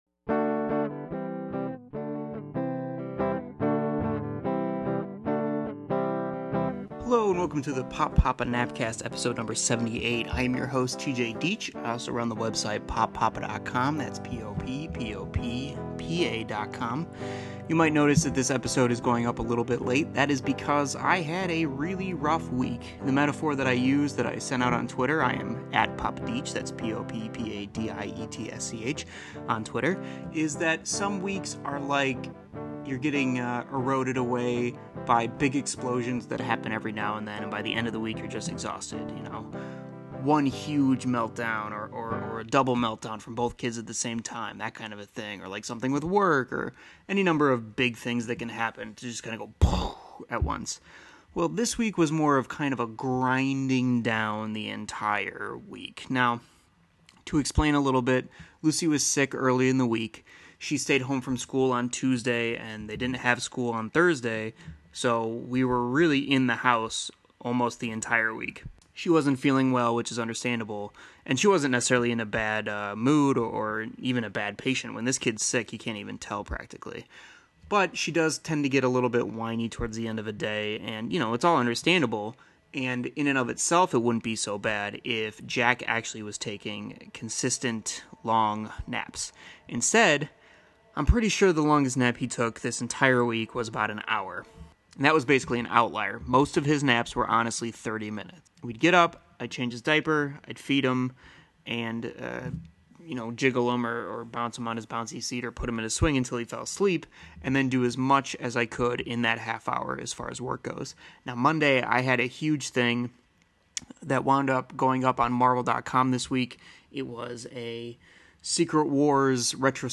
The Pop Poppa Nap Cast Episode 78 chronicles a grind of a week and the glory of The Muppet Babies. Plus you get to hear my bad imitations of Frank Oz voices.